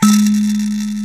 SANZA 3 G#2.WAV